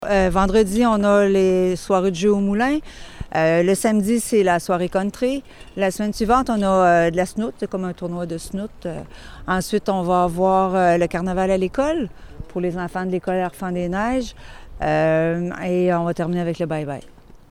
La 57e édition du Carnaval de Gentilly a été lancée mardi à l’hôtel de ville de Bécancour sous le thème Familial et amical.